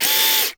CAMERA_DSLR_AutoFocus_Motor_01_mono.wav